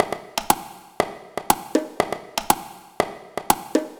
120_bongo_2.wav